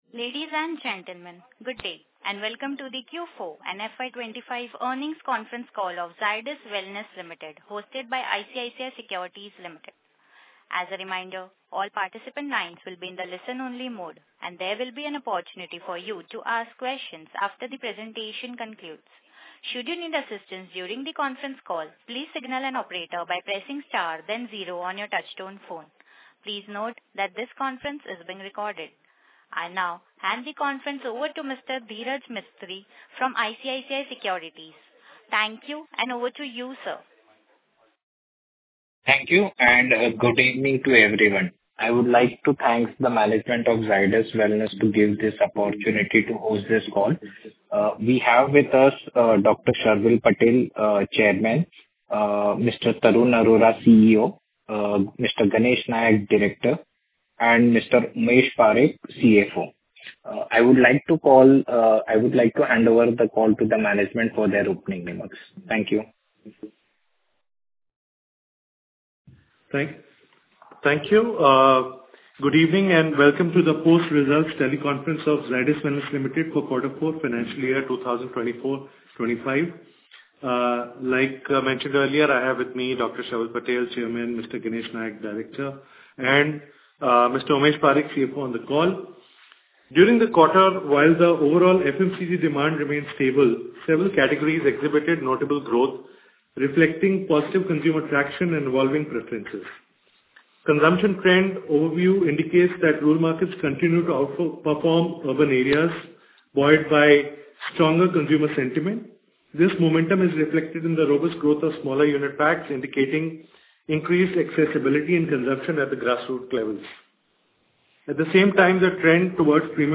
Investor Call Transcript – Q4 FY 25